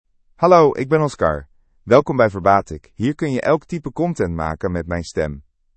Oscar — Male Dutch (Netherlands) AI Voice | TTS, Voice Cloning & Video | Verbatik AI
Oscar — Male Dutch AI voice
Oscar is a male AI voice for Dutch (Netherlands).
Voice sample
Listen to Oscar's male Dutch voice.
Oscar delivers clear pronunciation with authentic Netherlands Dutch intonation, making your content sound professionally produced.